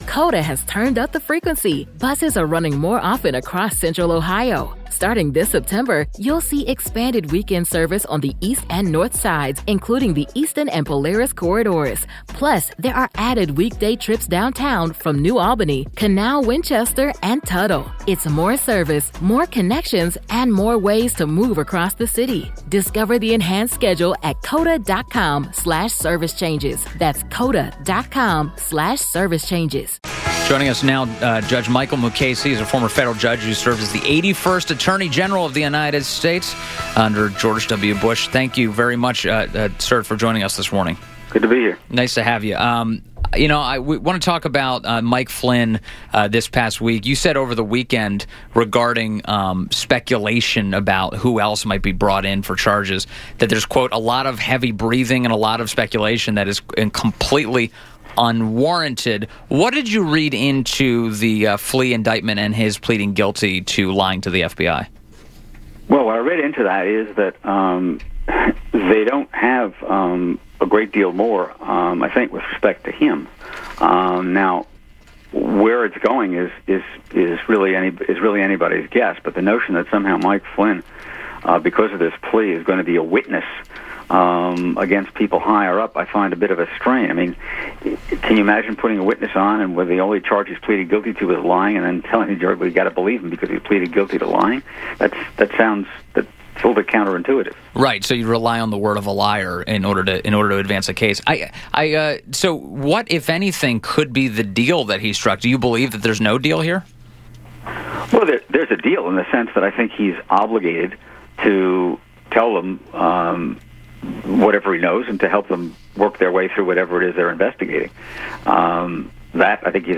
INTERVIEW - JUDGE MICHAEL MUKASEY - former federal judge who served as the 81st Attorney General of the United States